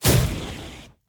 SFX / Spells
Firebuff 2.ogg